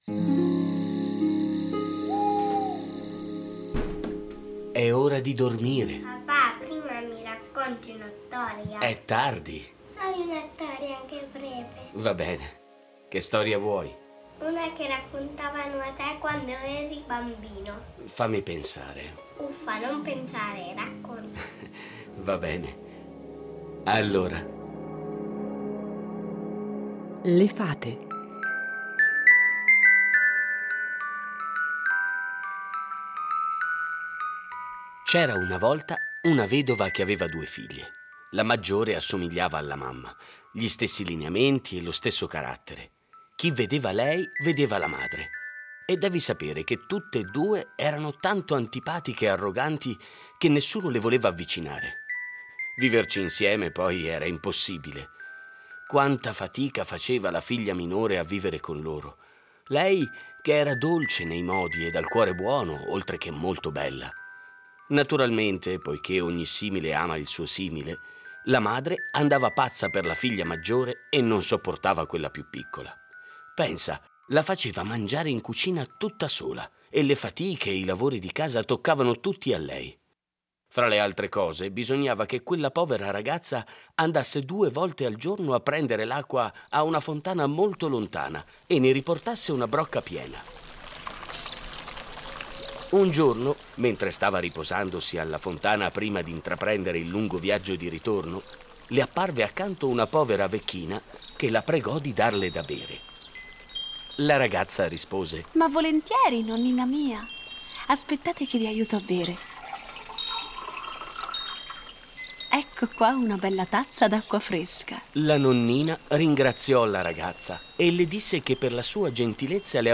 Fiabe
A partire dai testi originali un adattamento radiofonico per far vivere ai bambini storie conosciute, ma un po'dimenticate